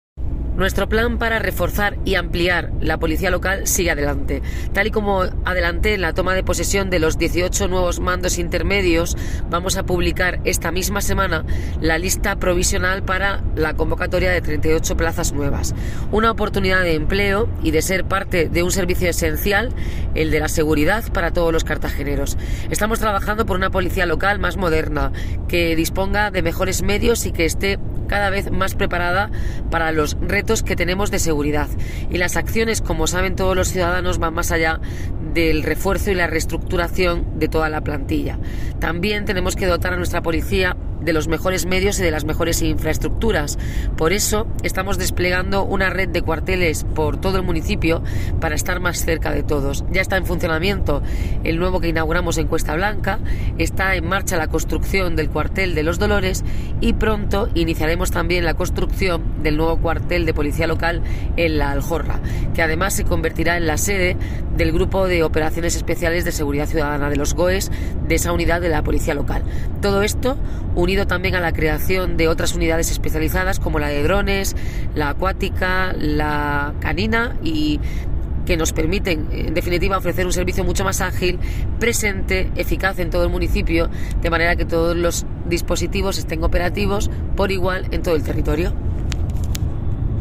Enlace a Declaraciones de Noelia Arroyo sobre convocatoria de plazas Policía Local